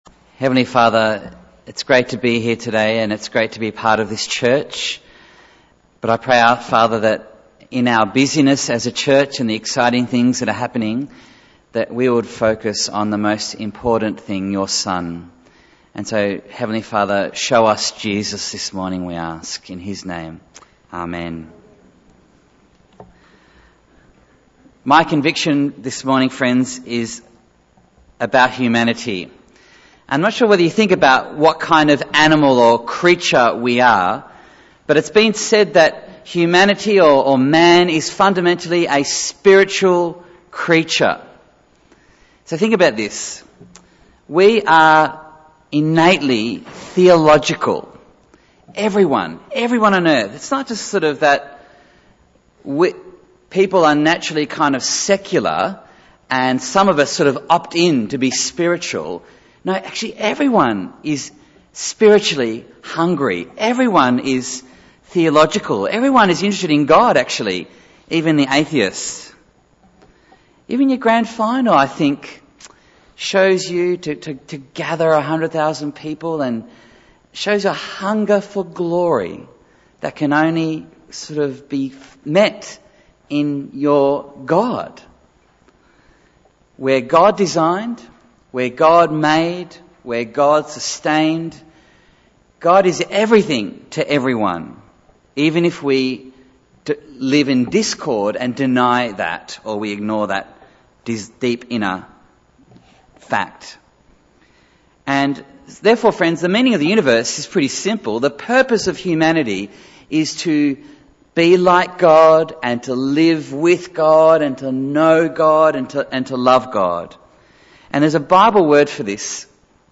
Bible Text: Romans 10:1-13 | Preacher